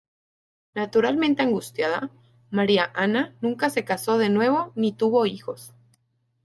Pronounced as (IPA) /ˈixos/